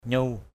/ɲu:/ (đ.) nó, hắn = it; him, her, them. mboh nyu lei?